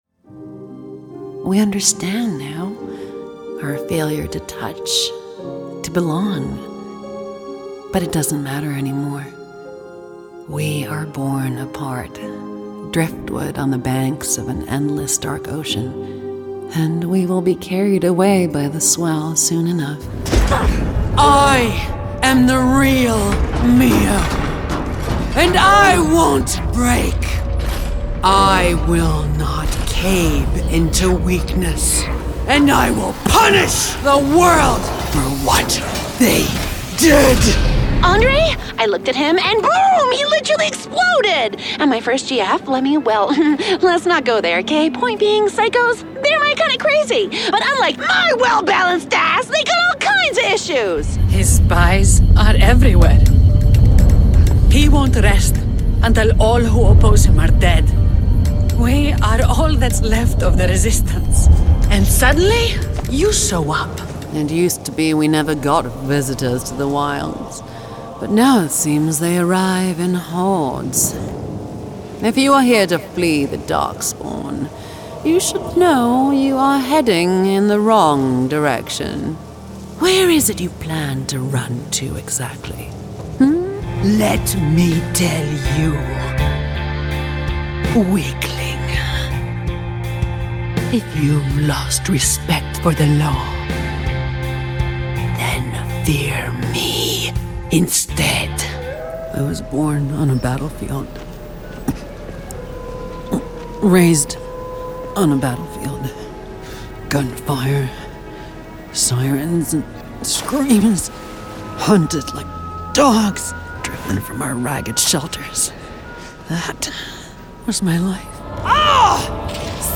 Video Game Showreel
Female
American Standard
Husky (light)
Soft
Warm